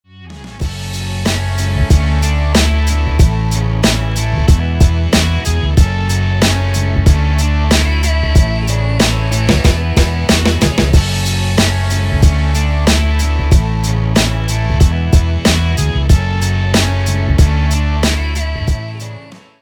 • Качество: 320, Stereo
без слов
красивая мелодия
инструментальные
Инструментальная вырезка из песни